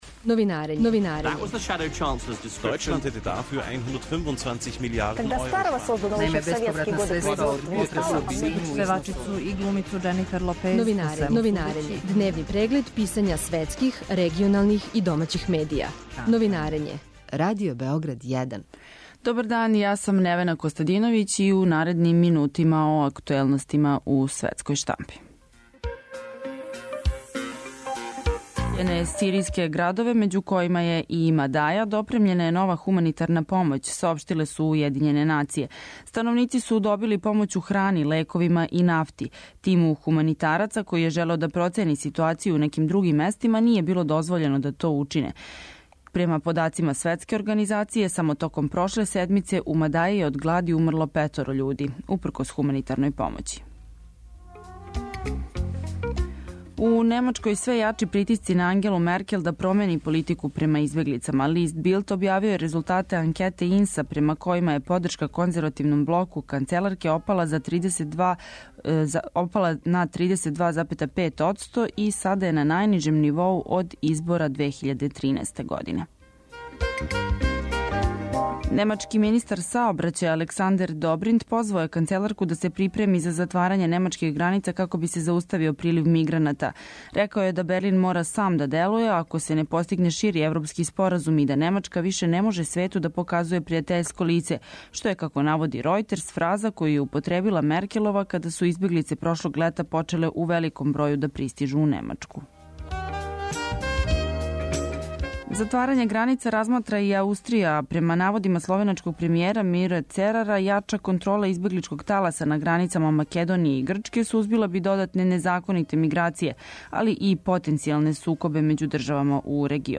Гост у студију је